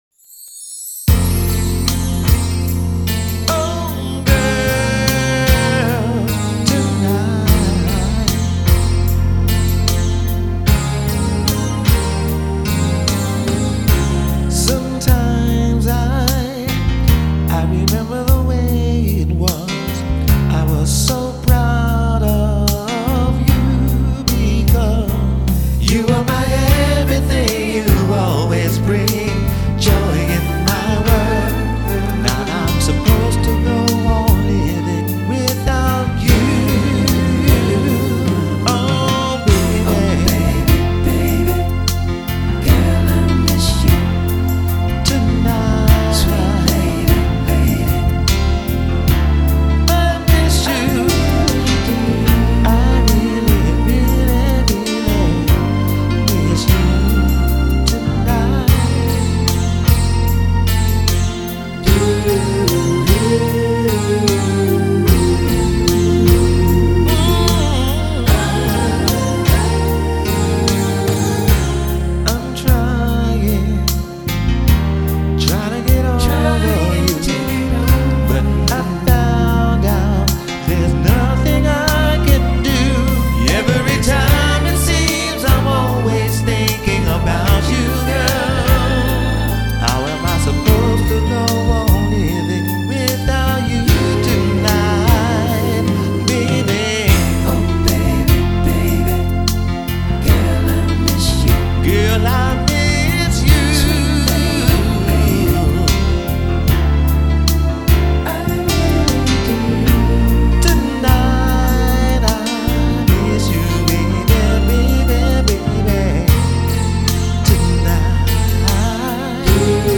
Home > Music > Rnb > Smooth > Medium > Laid Back